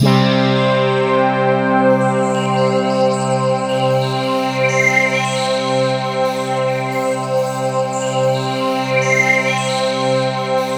BRASSPADC3-L.wav